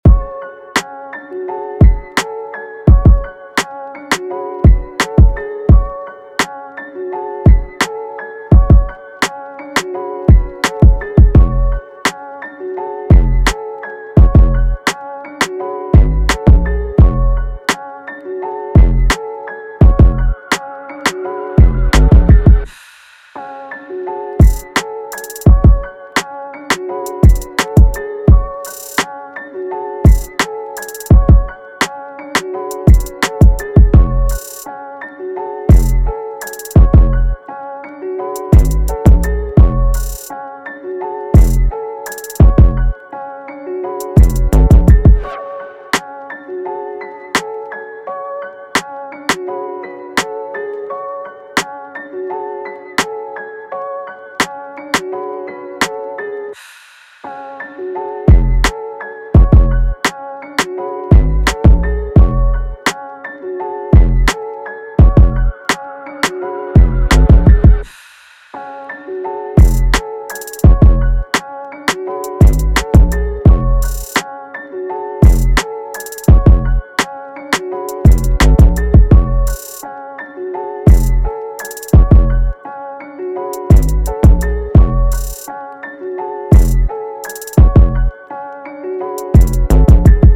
Hip Hop
D# Minor